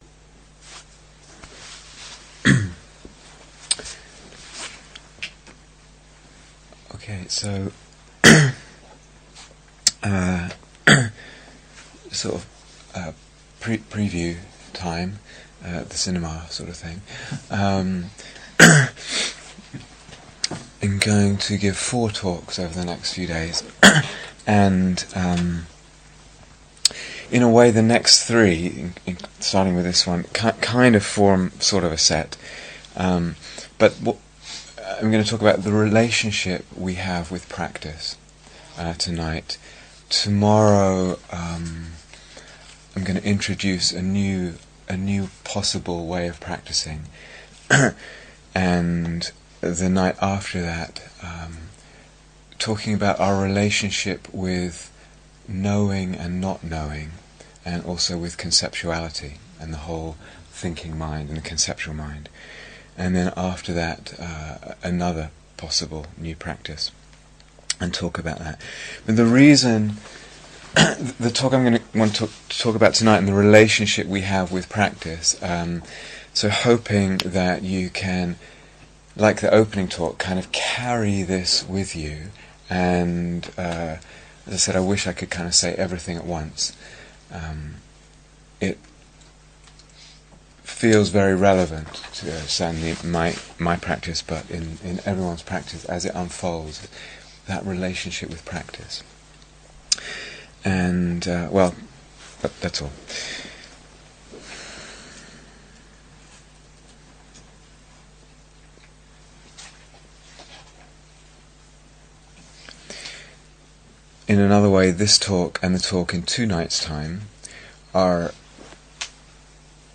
Here is the full retreat on Dharma Seed Please note that these talks are from a 4 week retreat for experienced meditators.